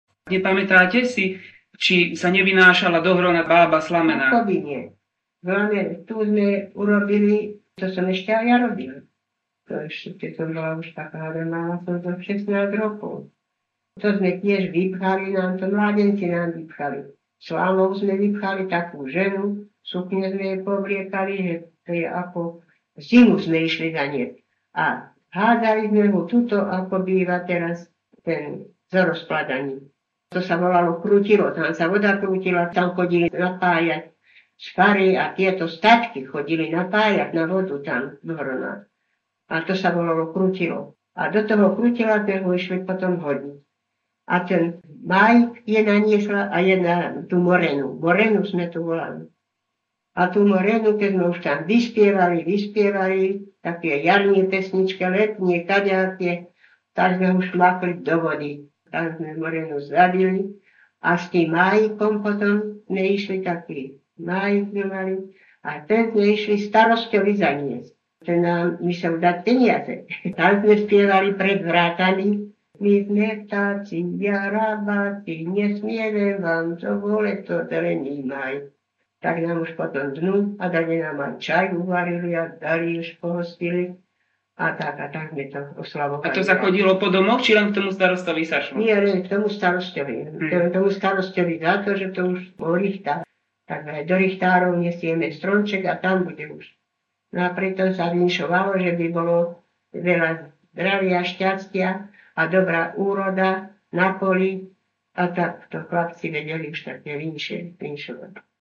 Vynášanie Moreny v Nemeckej 001-02